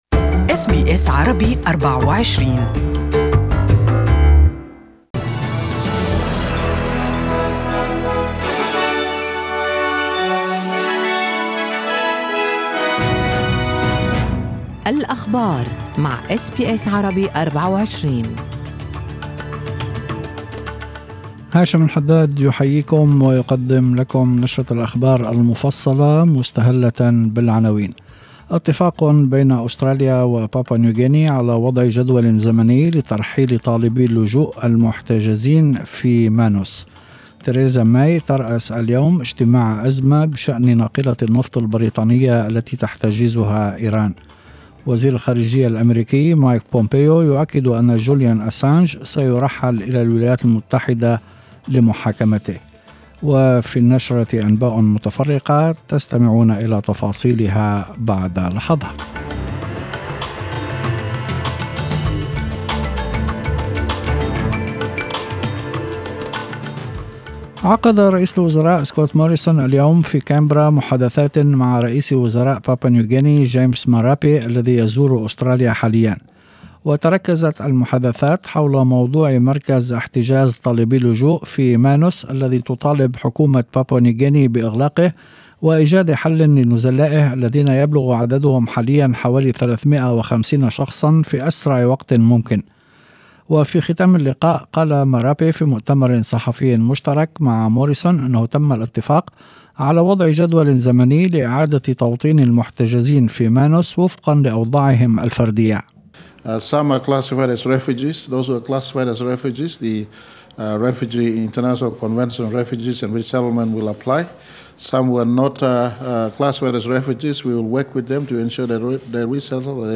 اخبار المساء: اتفاق بين استراليا و بابوا نيو غيني لترحيل طالبي اللجوء من مانوس